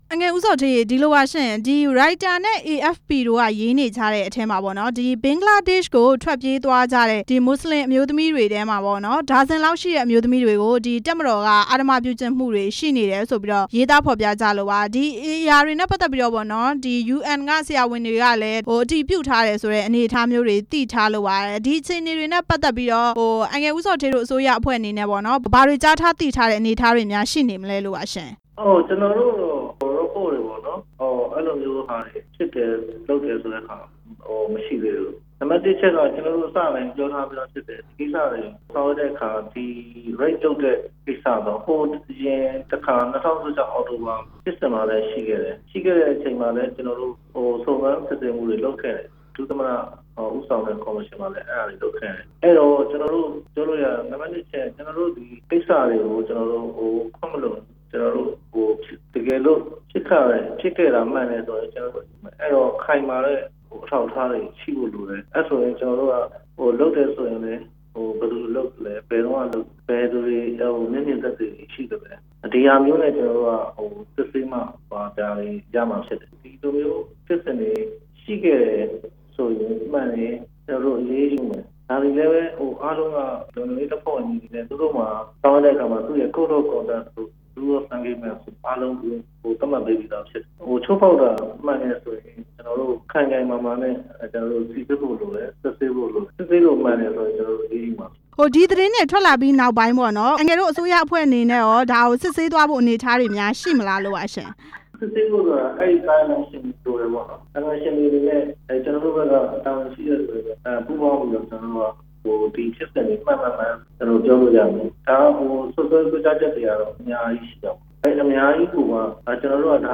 ရိုဟင်ဂျာ အမျိုးသမီးတချို့ အဓမ္မကျင့်ခံရမှု သတင်းအပေါ် မေးမြန်းချက်